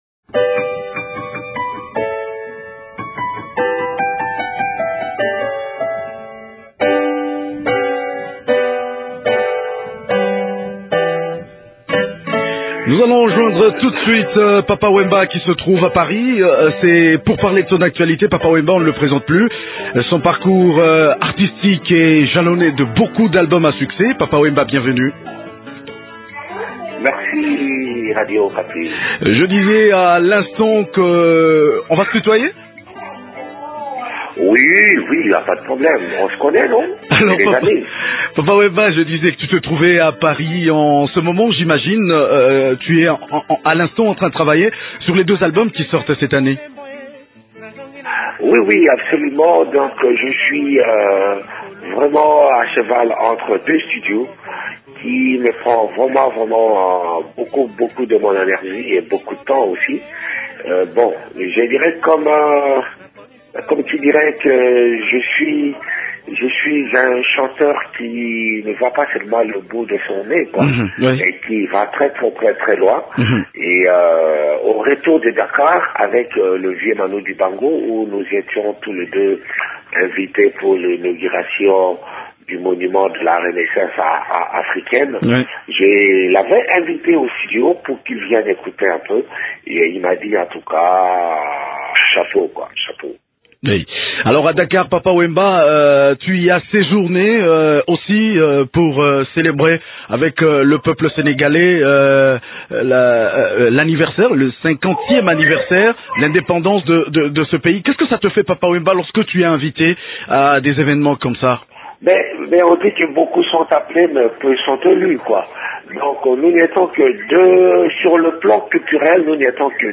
A cheval entre deux enregistrements à Paris, il a accordé une interview à Radio Okapi.